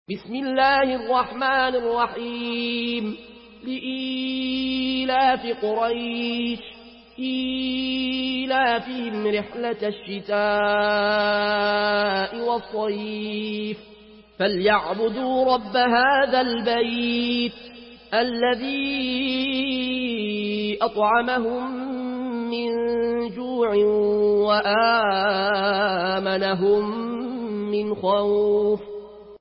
Surah Quraish MP3 in the Voice of Al Ayoune Al Koshi in Warsh Narration
Murattal